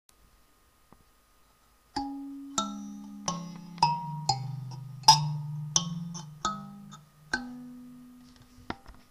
Mbira with rectangular resonance box. South Africa, Pretoria, Villieria
Type 6 levers with a resonance box
w_mbira.wma